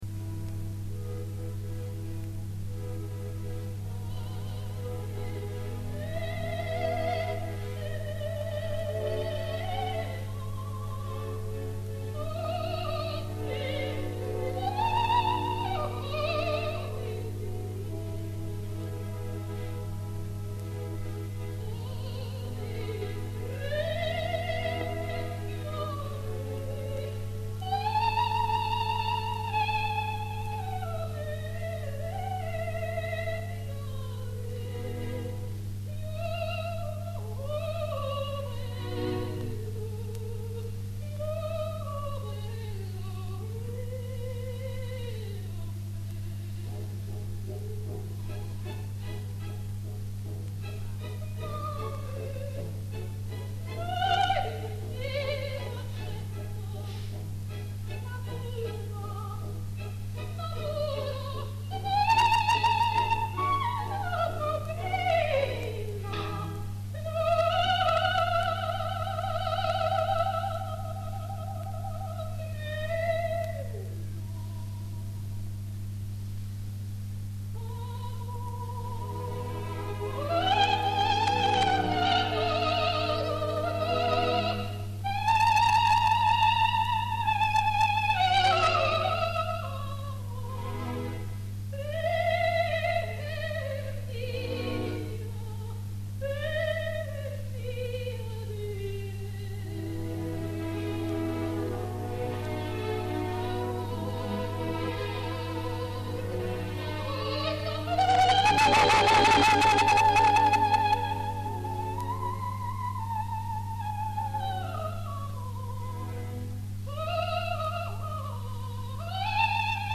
registrazione dal vivo /Colonia. Grosses Haus, 4 luglio 1957